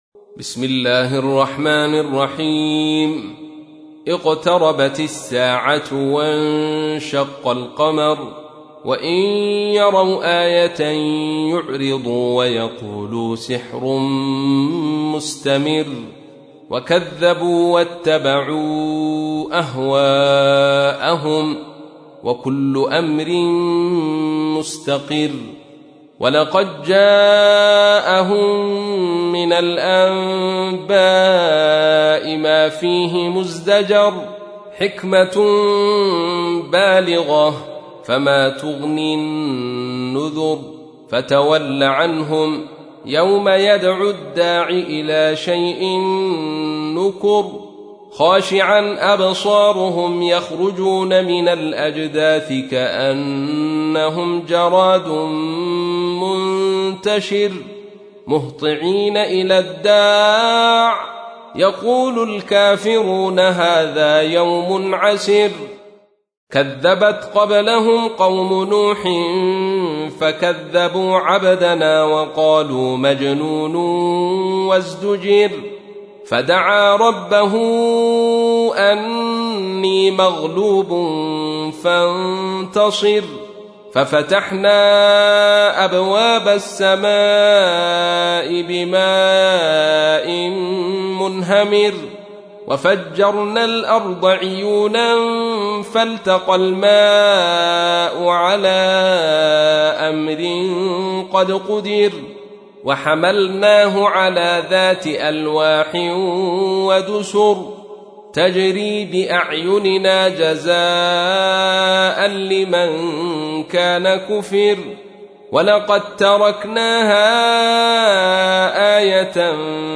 تحميل : 54. سورة القمر / القارئ عبد الرشيد صوفي / القرآن الكريم / موقع يا حسين